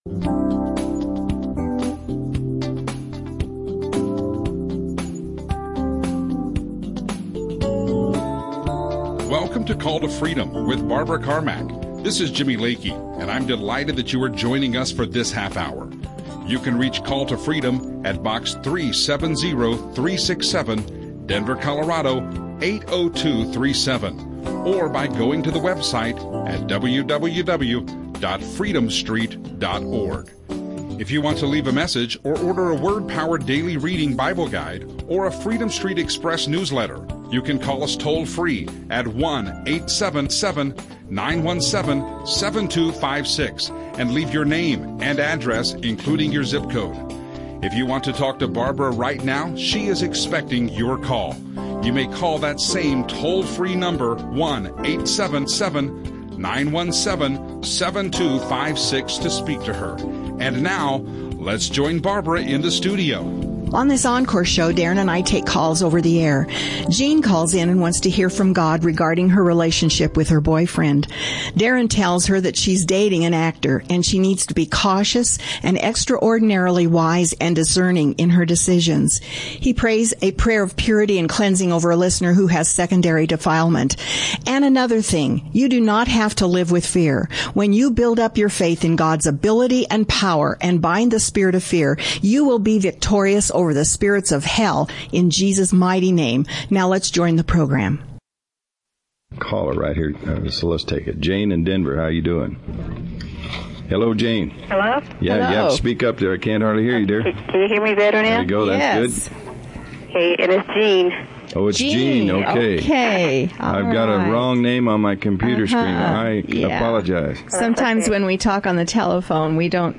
Christian radio show